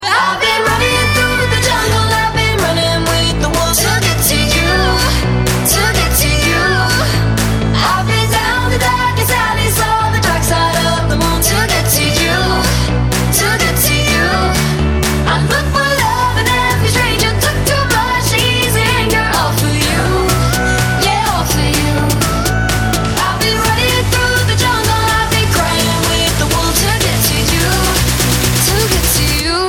• Качество: 320, Stereo
поп
громкие
женский вокал
зажигательные
Кусок классного зажигательного трека.